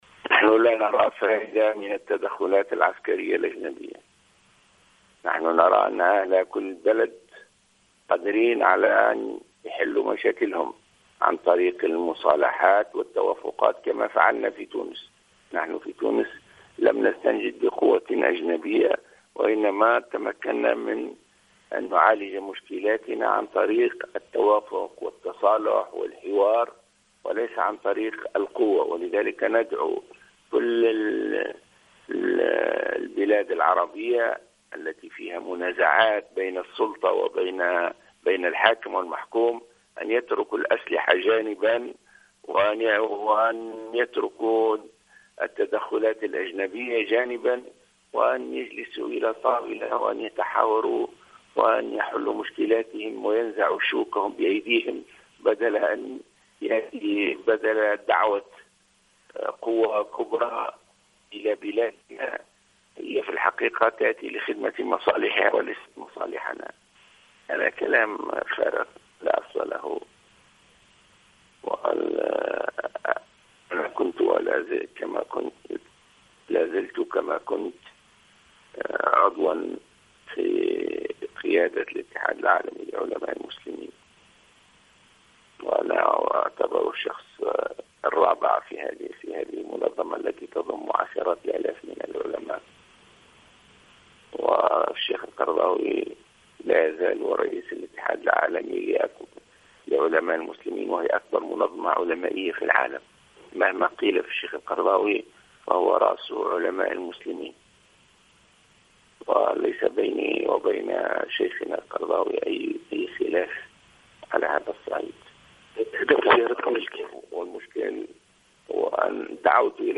Le leader du mouvement Ennahdha Rached Ghannouchi, a indiqué, lors d’une déclaration accordée au correspondant de Jawhara FM ce dimanche 4 octobre 2015, que son parti soutient, à priori, le projet de loi sur la réconciliation économique.